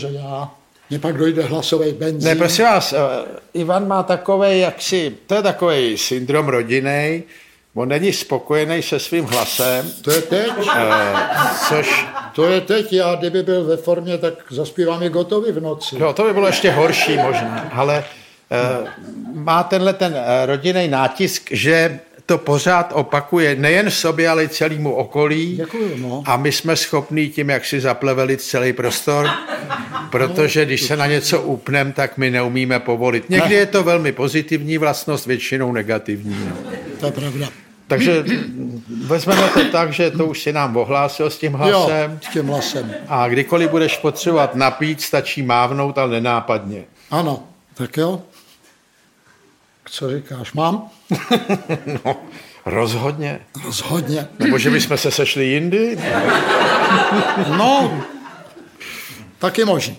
Audiobook
Read: Jan Kraus